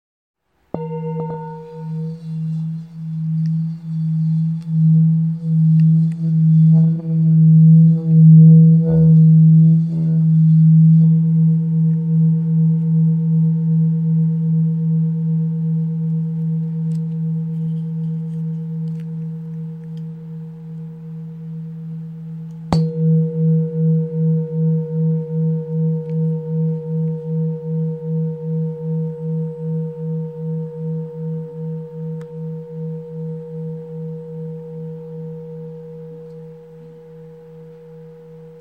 Cymbales Tibétaines "Plain" Extra Qualité 7,5cm artisanat
Cymbales Tibétaines "Plain" Extra Qualité 6,5cm de diamètre Poids d'environ 300g Couleur bronze
Le son de l'enregistrement proposé est indicatif et peut sensiblement varier d'une cymbale à l'autre, il reflète la qualité sonore de l'instrument.